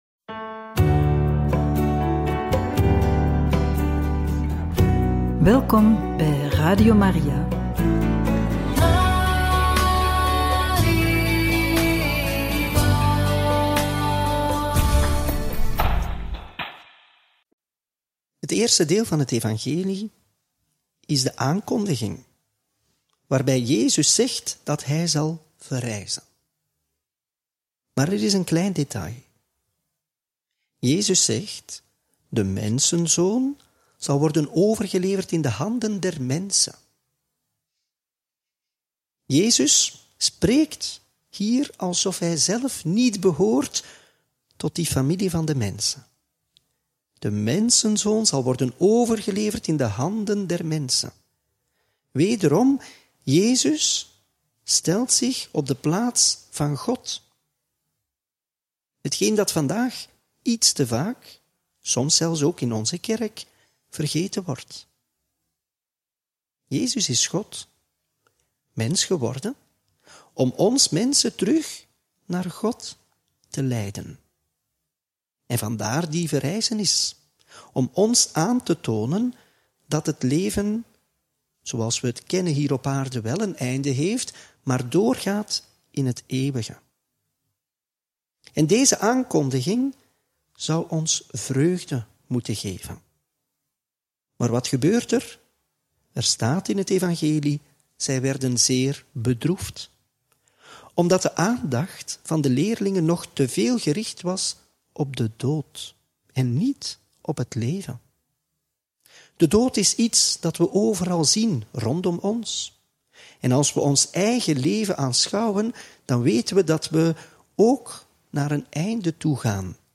Homilie bij het Evangelie van maandag 11 augustus 2025 – Mt 17, 22-27